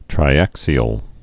(trī-ăksē-əl)